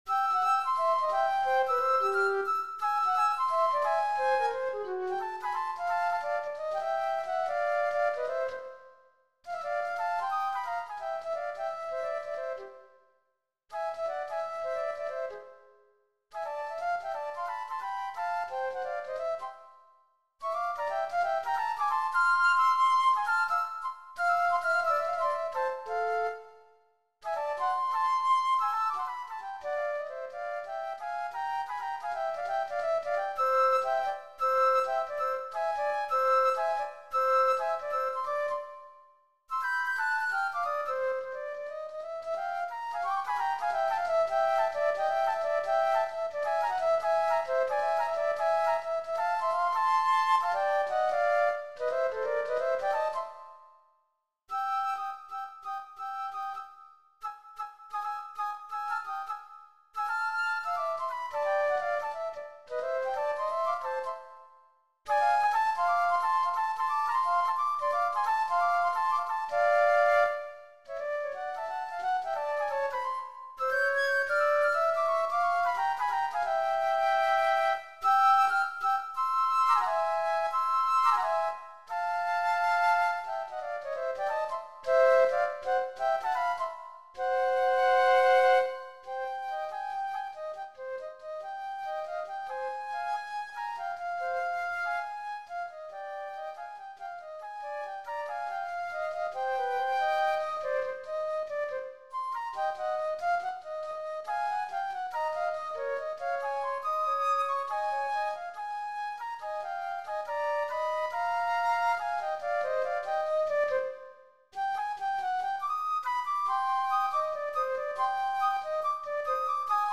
Gattung: Für 2 Flöten
Besetzung: Instrumentalnoten für Flöte